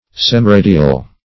Semiradial \Sem`i*ra"di*al\, a.
semiradial.mp3